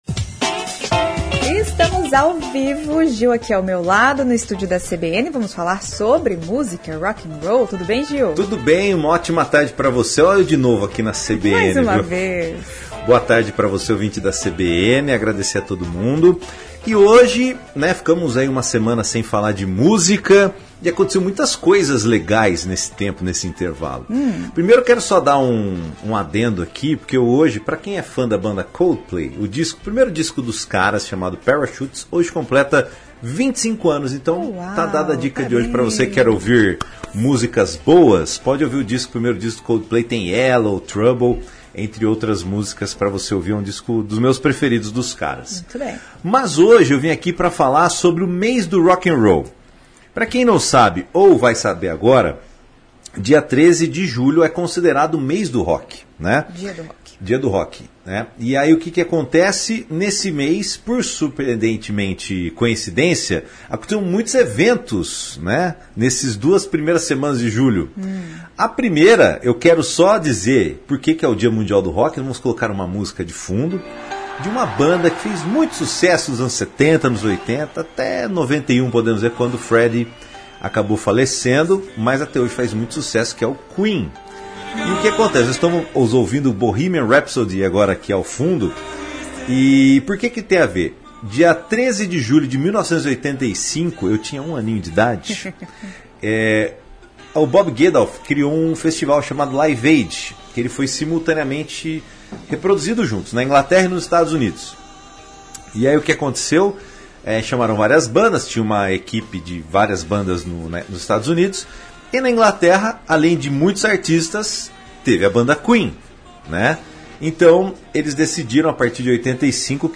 Bate papo sobre o Dia do Rock, despedida de Ozzy e retorno do Oasis